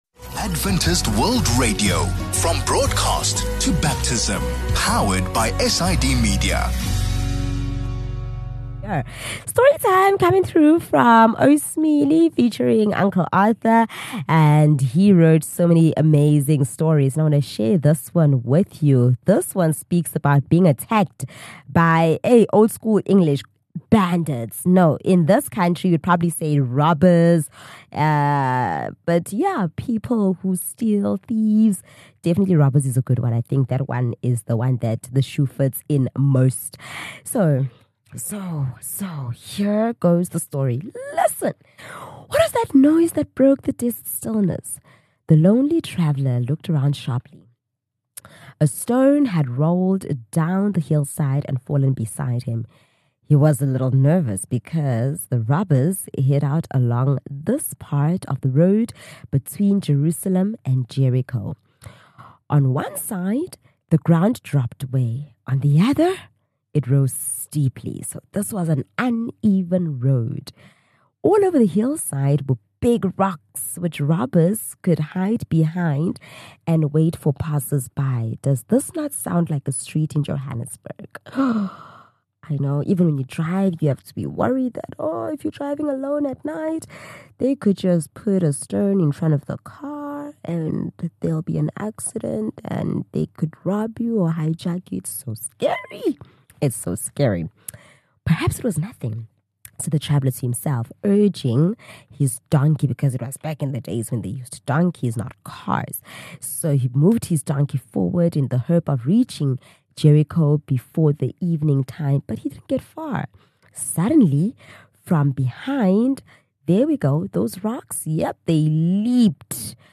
28 Mar Children's Story | Be good to everyone without any expectations